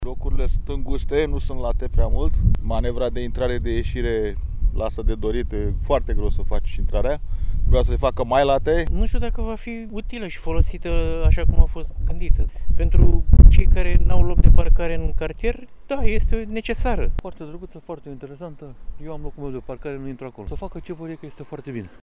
Opiniile constănțenilor intervievați de jurnaliștii Radio Constanța sunt împărțite: